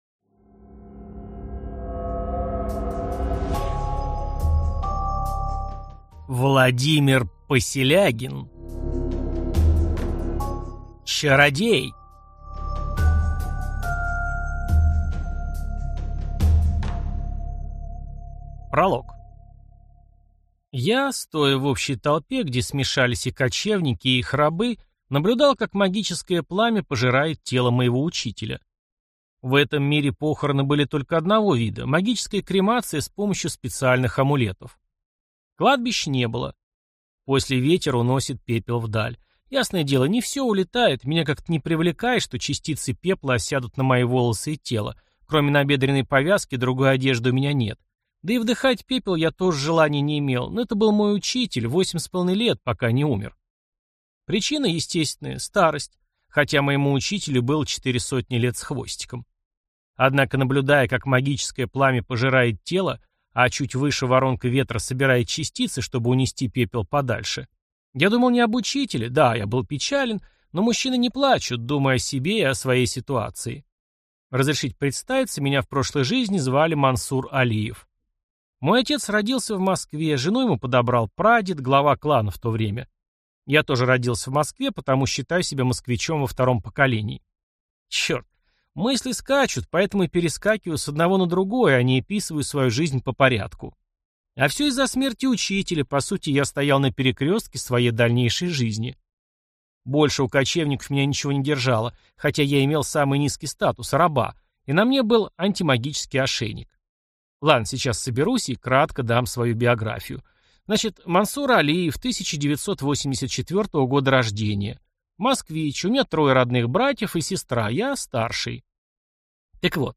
Аудиокнига Чародей | Библиотека аудиокниг